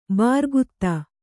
♪ bārgutta